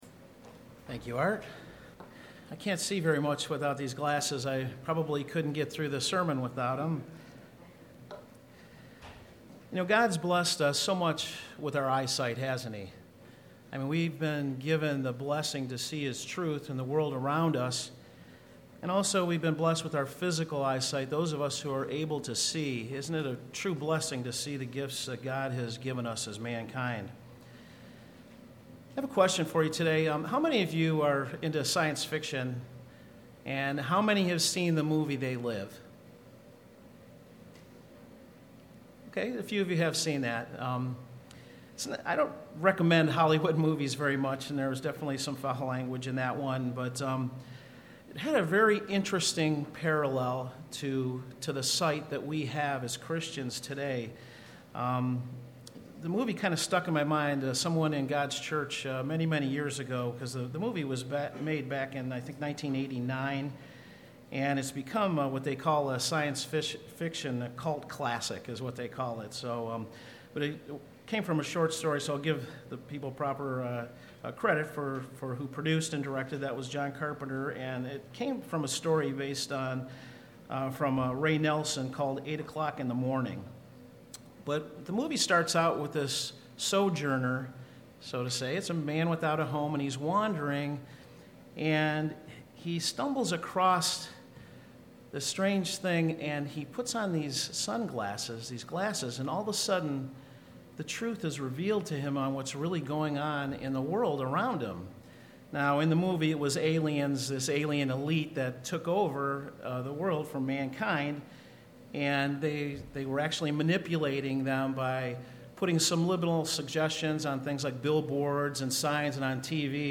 Sermons
Given in Cleveland, OH